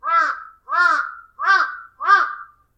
cuervo7
crow7.mp3